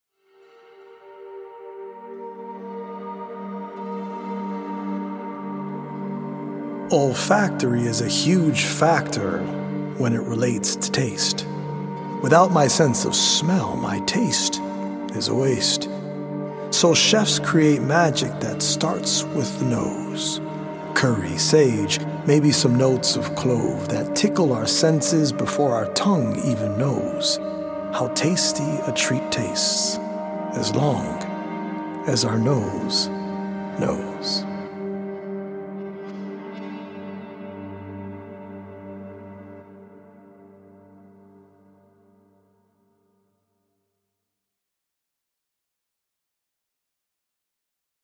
Senses is a healing audio-visual poetic journey through the mind-body and spirit that is based on 100 original poems written/performed by
healing Solfeggio frequency music